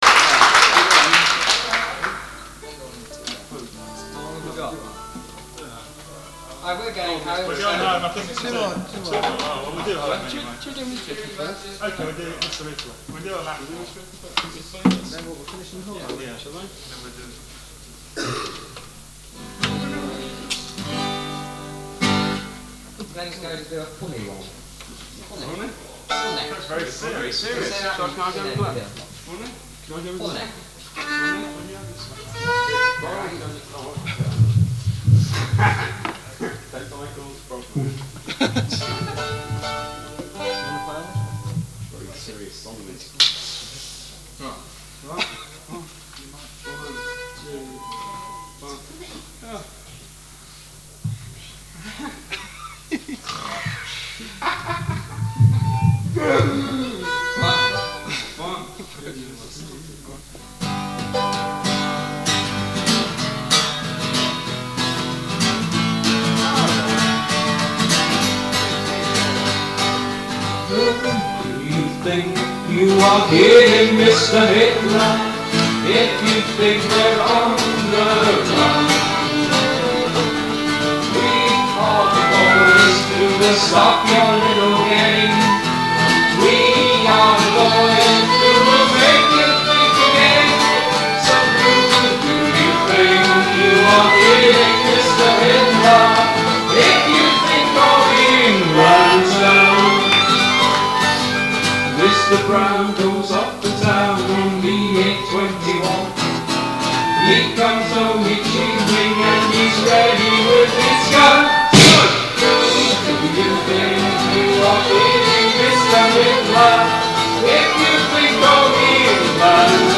Guitars, Bass, Percussion, Vocals